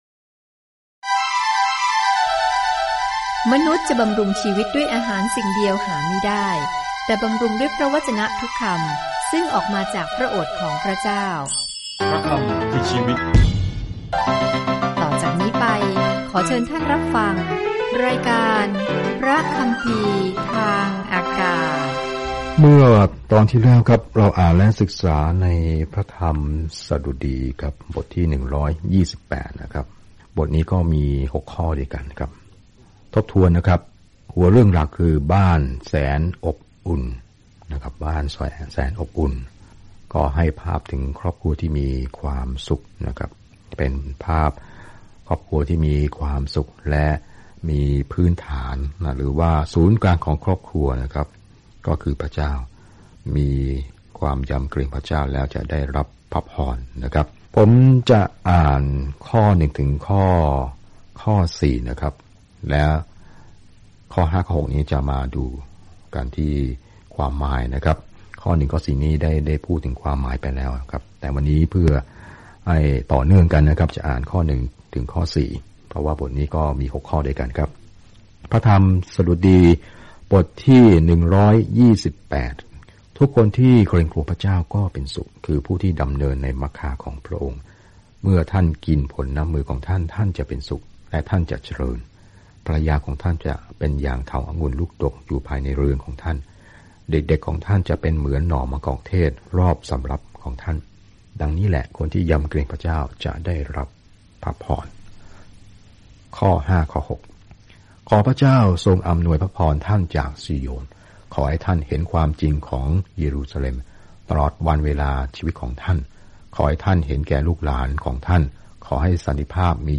เดินทางทุกวันผ่านเพลงสดุดีในขณะที่คุณฟังการศึกษาด้วยเสียงและอ่านข้อที่เลือกจากพระวจนะของพระเจ้า